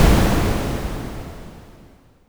Added sound effects to Cannon variable server client.
CannonBoom.wav